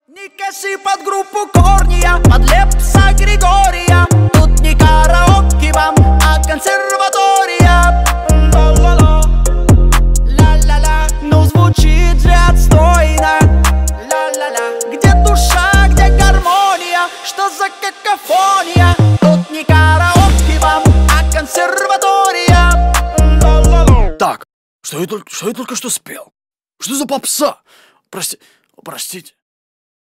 громкие